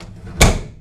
Index of /DESN275/soundLibrary/doorSounds
DOOR_156.AIF